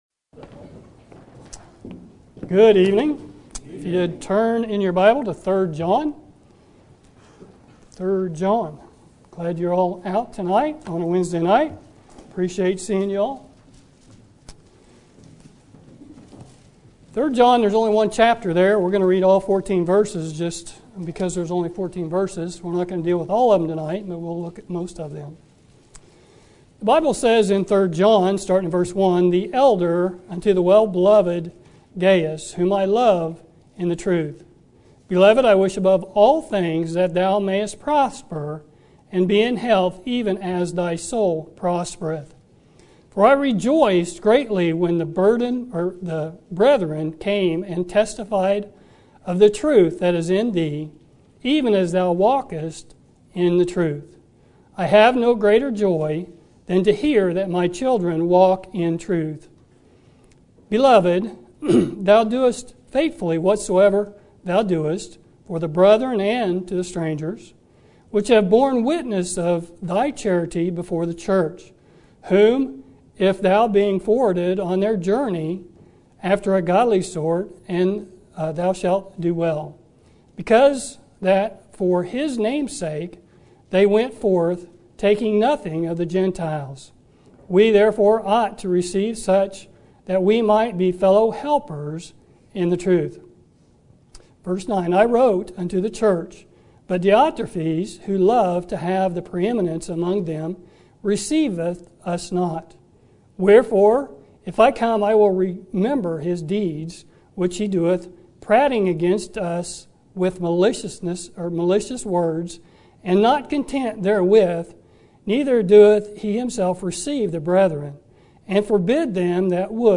Sermon Topic: General Sermon Type: Service Sermon Audio: Sermon download: Download (26.13 MB) Sermon Tags: 3 John Gaius Diotrephes Service